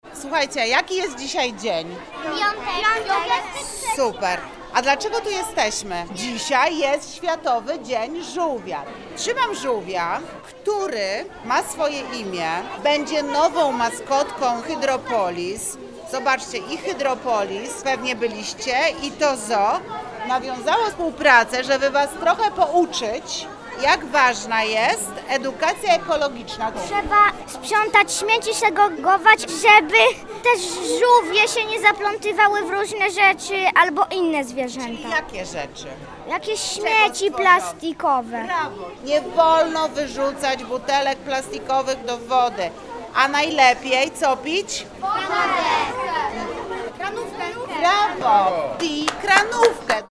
– Instytucje nawiązały współpracę, by wspólnie edukować w zakresie ekologii, mówi wiceprezydent Wrocławia, Renata Granowska.